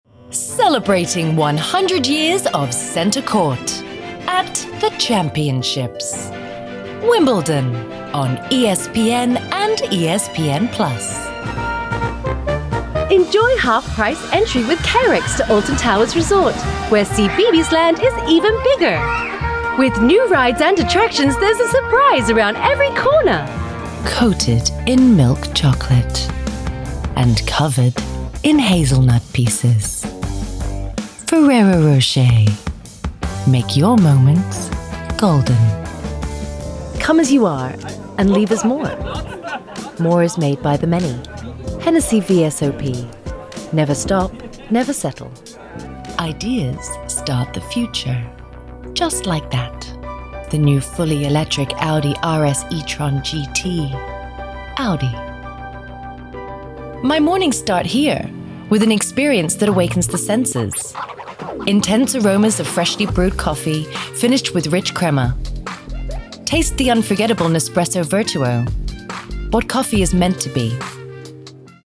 Commercial Work
My natural accent is neutral with a lean to British/Australian.
Young Adult
Middle Aged
Commercial Demo Reel 1 FINAL FILE_01.mp3